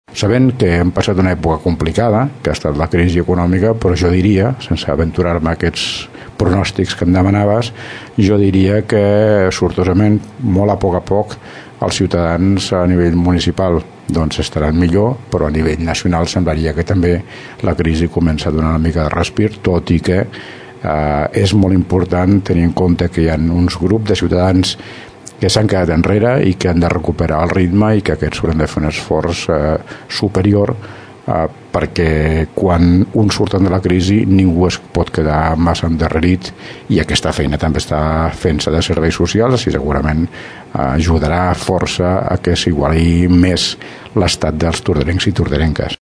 entrevista alcaldeDarrera valoració d’aquest primera meitat de legislatura amb l’alcalde de Tordera, Joan Carles Garcia del PDeCAT.